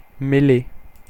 Ääntäminen
IPA : /ˈmɛl.eɪ/ US : IPA : [ˈmɛl.eɪ]